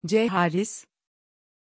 Pronunciation of Charis
pronunciation-charis-tr.mp3